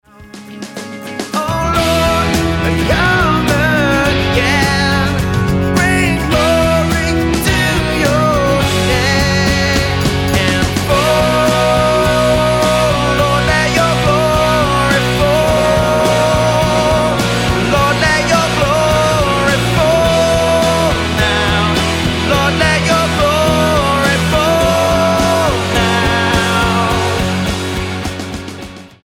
Rock Album
Style: Pop Approach: Praise & Worship